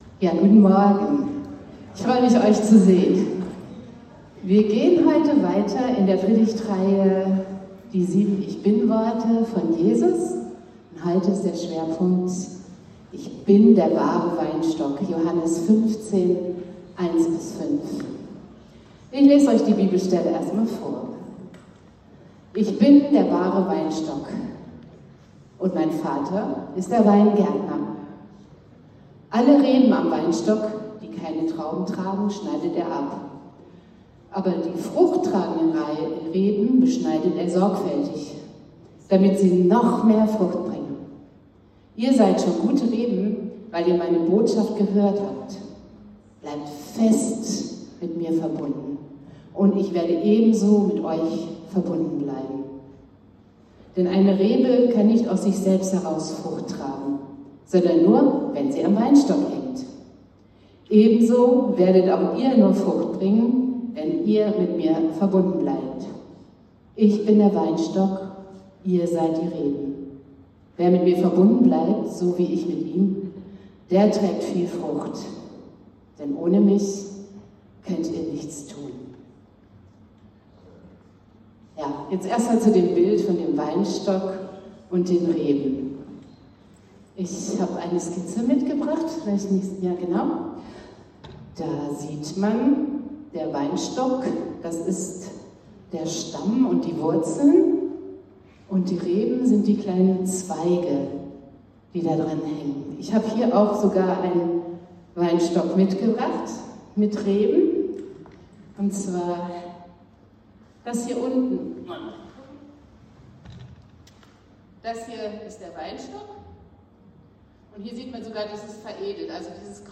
Predigtreihe die 7 Ich bin-Worte von Jesus